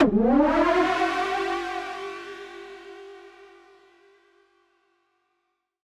Index of /musicradar/future-rave-samples/Siren-Horn Type Hits/Ramp Up
FR_SirHornD[up]-G.wav